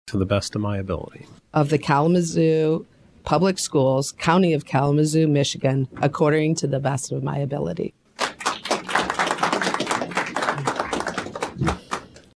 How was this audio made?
She took the oath last night after having been appointed to replace a board member who resigned last month.